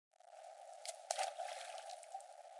2017年的声音设计" 飞溅的编辑
描述：在池塘里溅起的声音
Tag: 小溪 飞溅